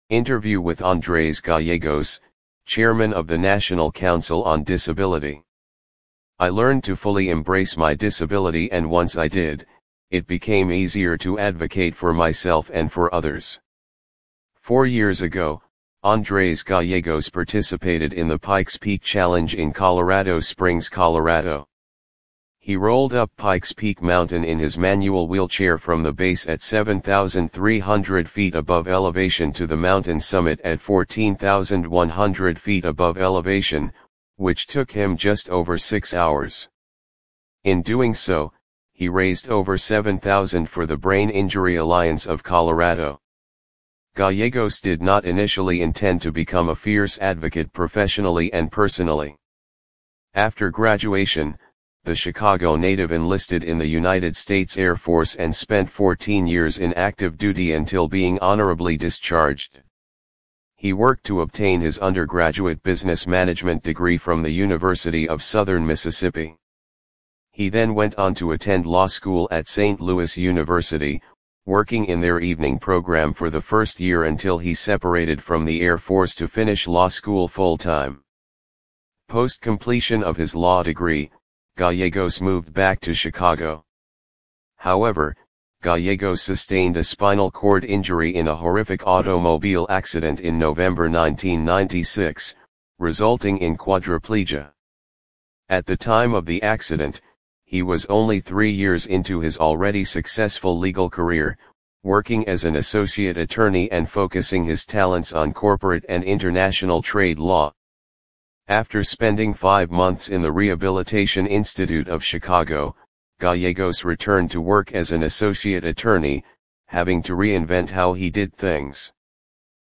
Interview-with-Andres-Gallegos-Chairman-of-the-National-Council-on-Disability.mp3